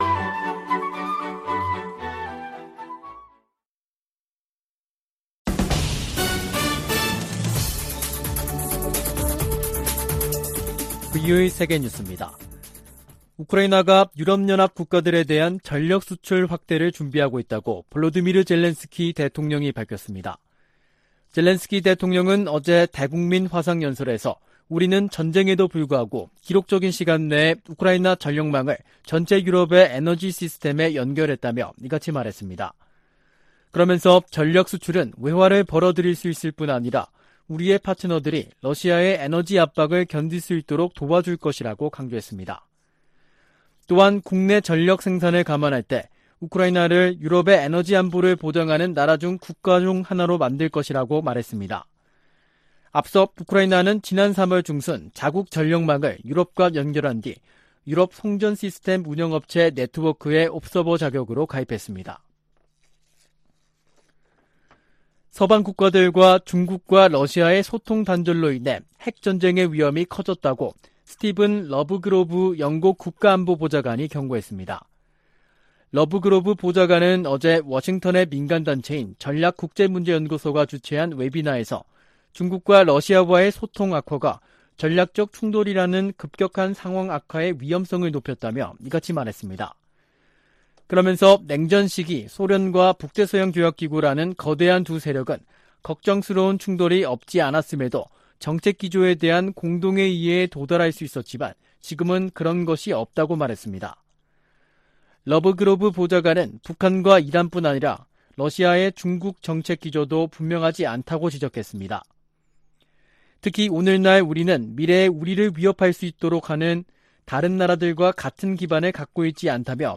VOA 한국어 간판 뉴스 프로그램 '뉴스 투데이', 2022년 7월 28일 2부 방송입니다. 미국은 북한 정부 연계 해킹조직 관련 정보에 포상금을 두배로 올려 최대 1천만 달러를 지급하기로 했습니다. 북한의 핵 공격 가능성이 예전에는 이론적인 수준이었지만 이제는 현실이 됐다고 척 헤이글 전 미 국방장관이 평가했습니다. 김정은 북한 국무위원장이 미국과 한국을 강력 비난하고, 미국과의 군사적 충돌에 철저히 준비할 것을 다짐했다고 관영 매체들이 보도했습니다.